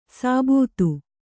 ♪ sābūtu